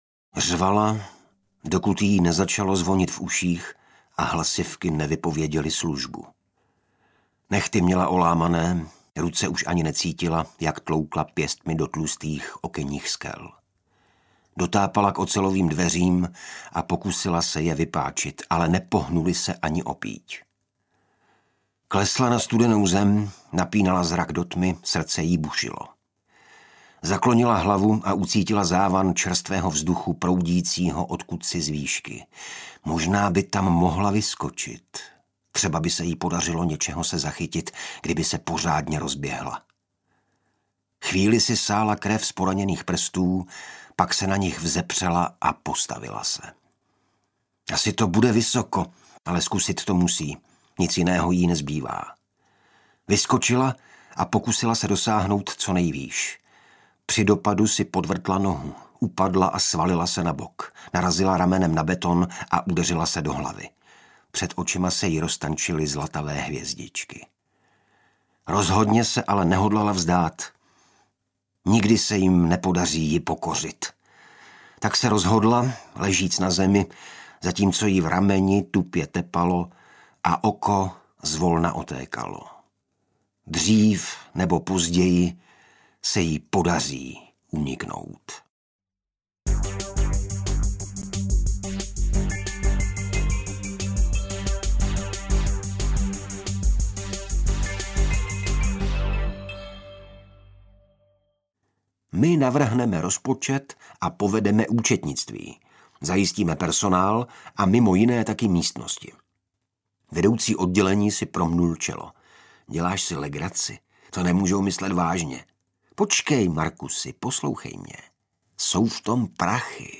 Audiokniha Dokonalá kořist – druhý díl drsné krimi série s detektivem Lucem Callanachem a inspektorkou Avou Turnerovou.
Čte Jan Šťastný.